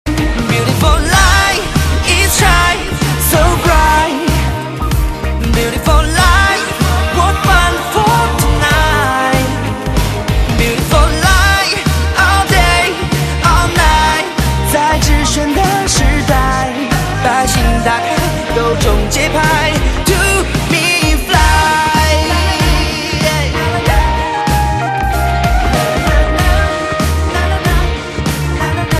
M4R铃声, MP3铃声, 华语歌曲 74 首发日期：2018-05-16 00:36 星期三